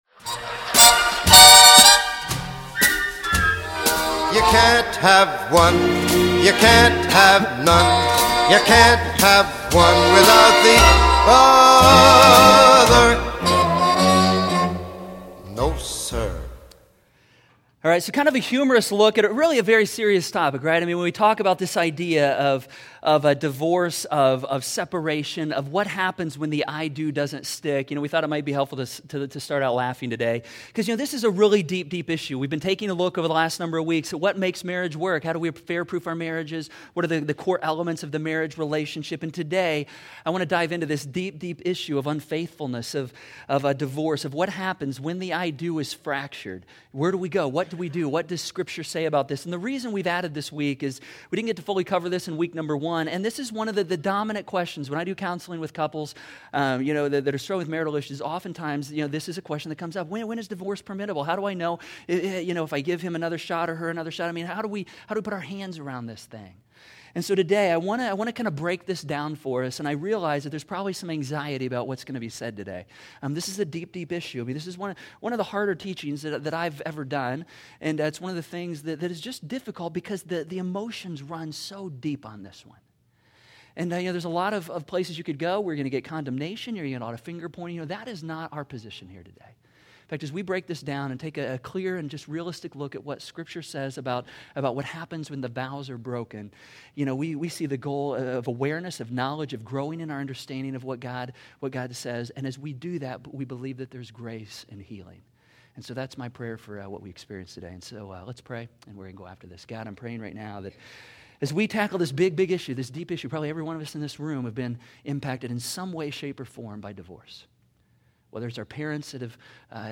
The links listed below were mentioned in today’s teaching.